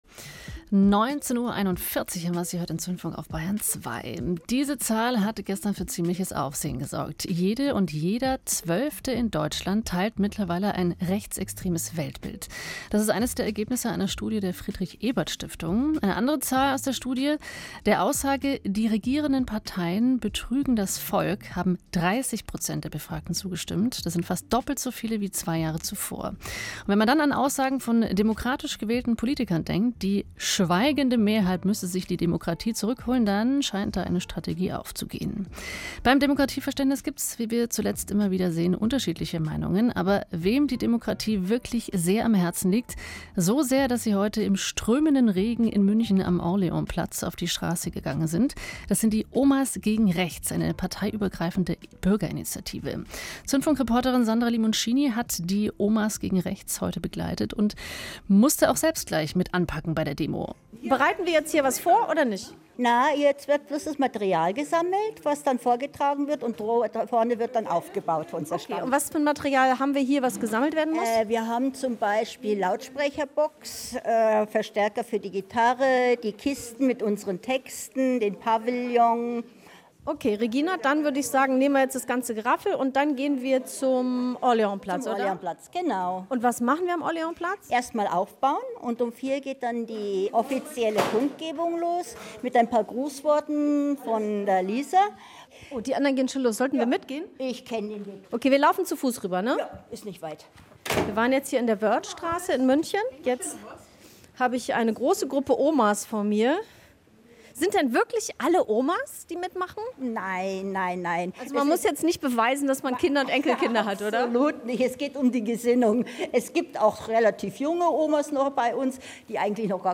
Bei der Landtagswahl Aktion der OgR am Orleansplatz im September 2023